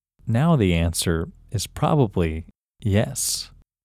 OUT – English Male 27